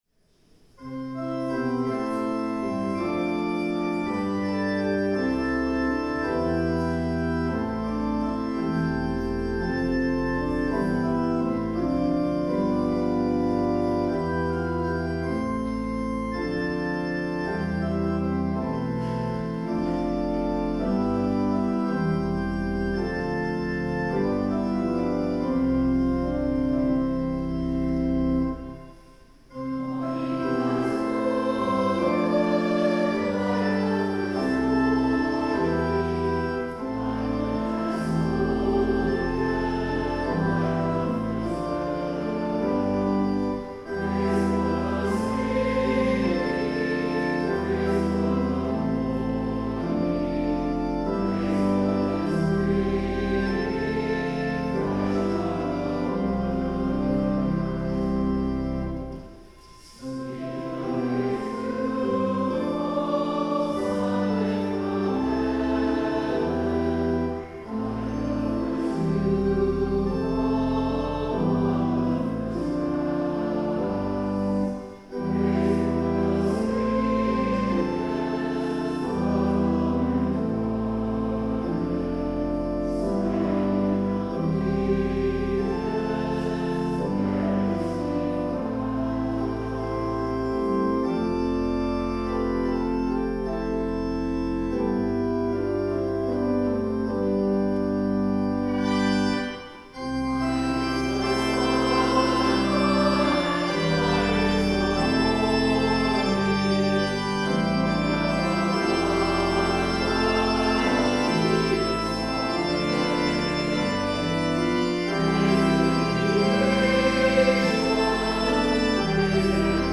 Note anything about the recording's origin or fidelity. Fifth Sunday of Easter